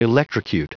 Prononciation du mot electrocute en anglais (fichier audio)
Prononciation du mot : electrocute